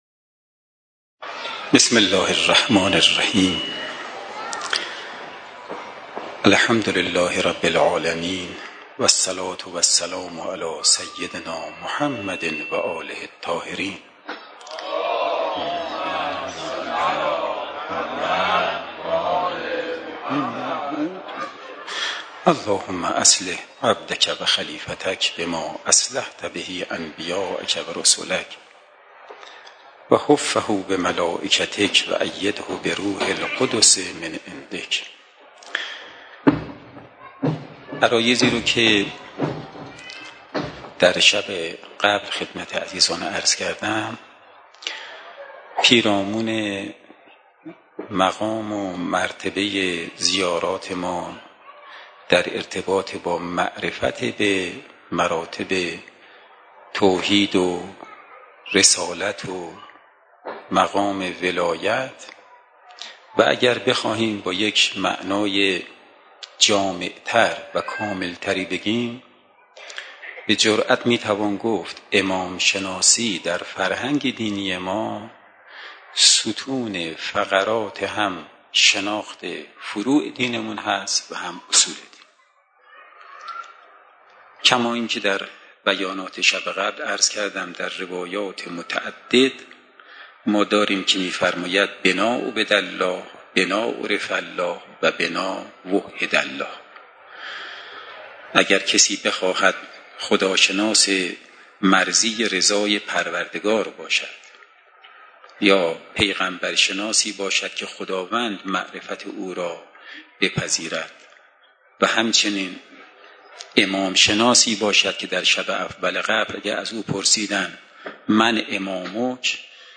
سخنرانی
سخنرانی شب دوم محرم